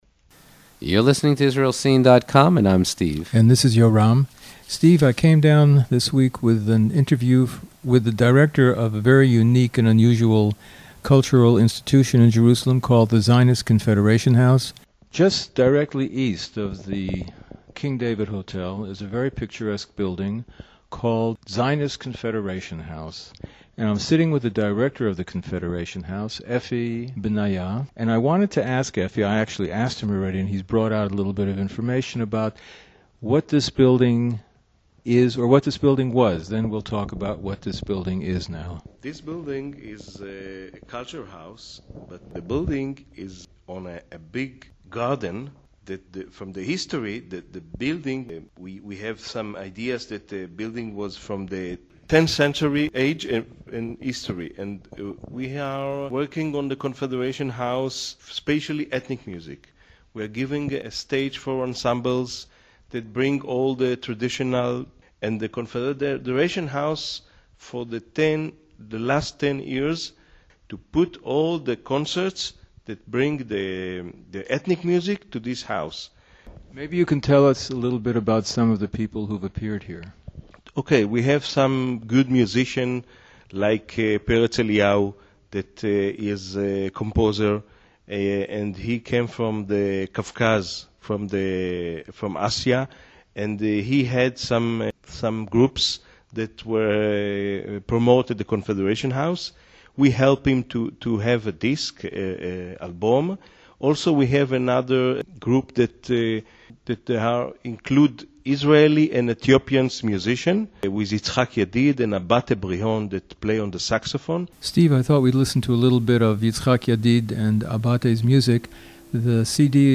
Interview at Zionist Confederation House